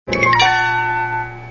Minimize 1 (10K)